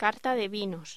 Locución: Carta de vinos
voz